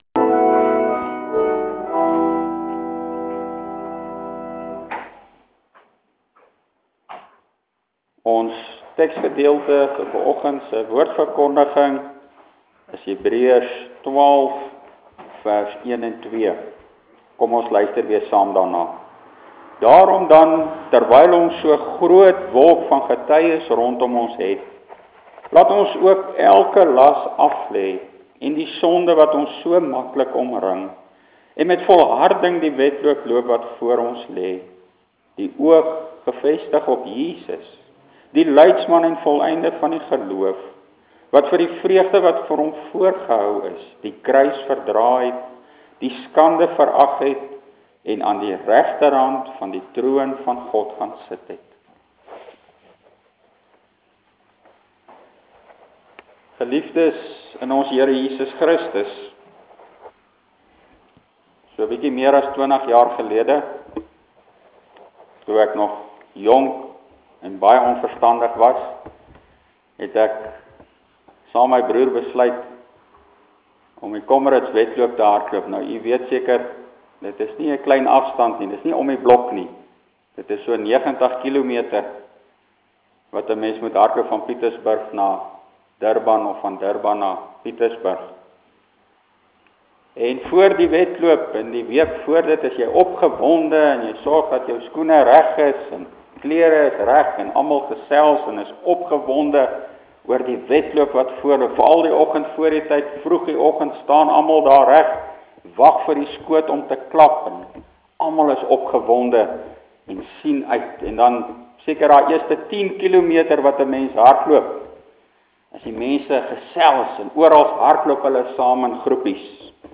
Preek van 10.01.2010 – Hebreërs 12:1,2
Hier is ‘n preek wat ek gelewer het op 10 Januarie 2010: Die oog gevestig op Jesus